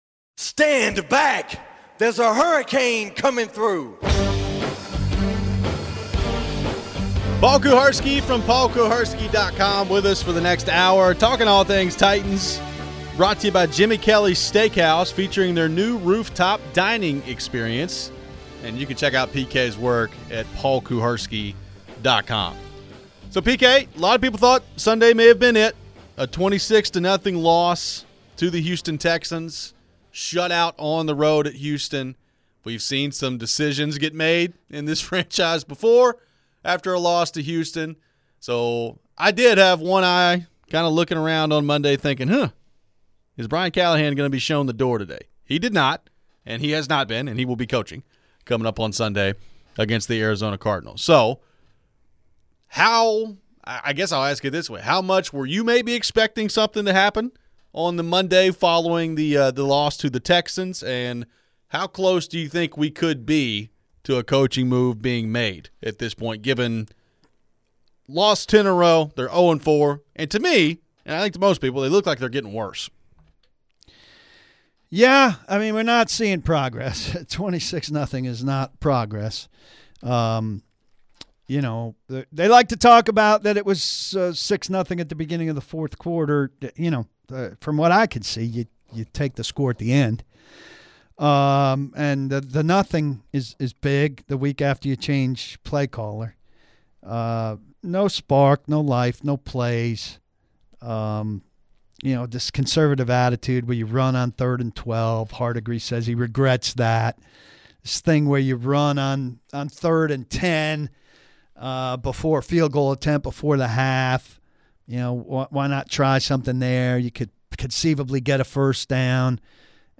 We take plenty of your phones.